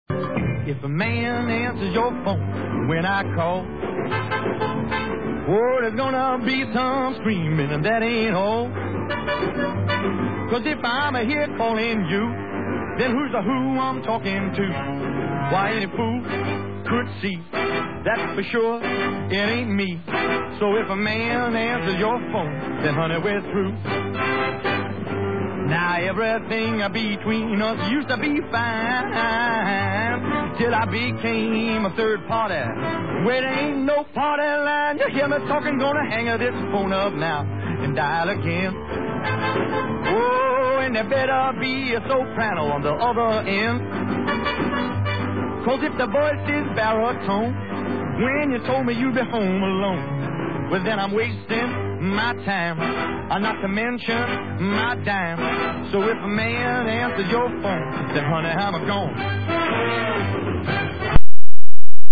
Bobby discussing this film. (Audio)